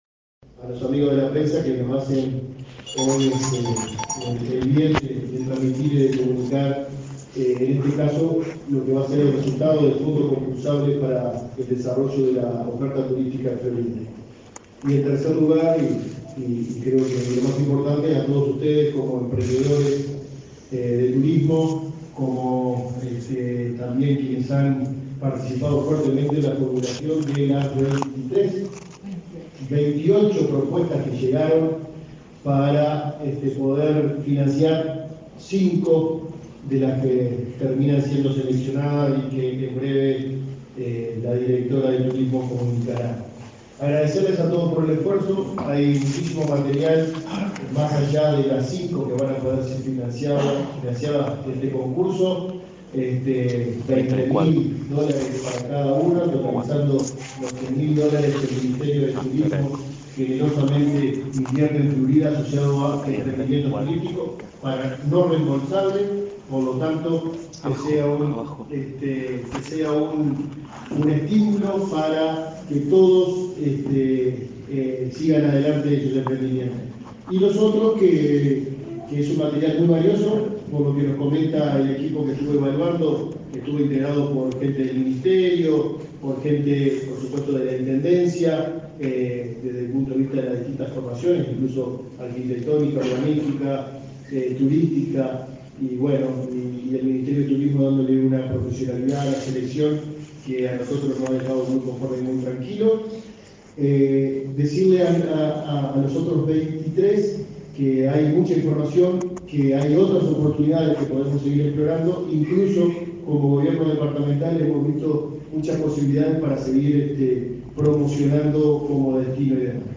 Conferencia de prensa por la presentación de proyectos turísticos de Fondos Concursables en Florida